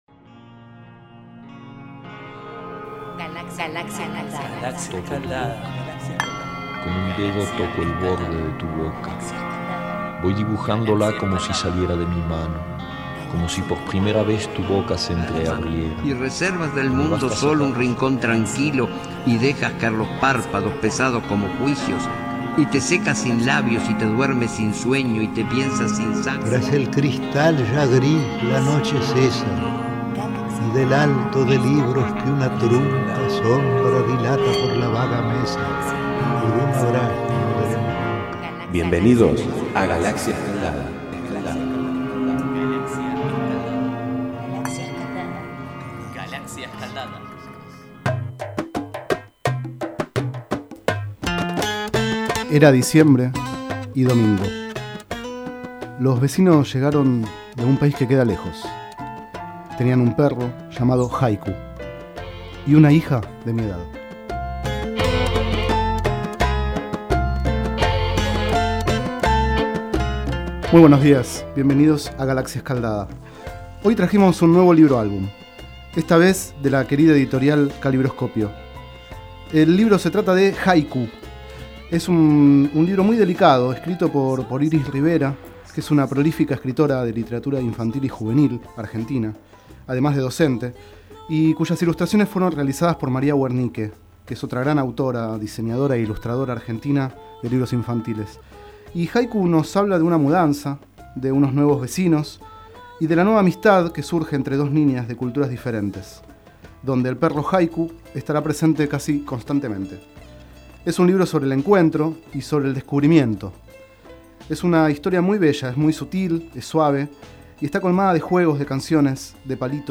Este es el 28º micro radial, emitido en los programas Enredados, de la Red de Cultura de Boedo, y En Ayunas, el mañanero de Boedo, por FMBoedo, realizado el 24 de septiembre de 2011, sobre el libro Haiku, de Iris Rivera y María Wernicke.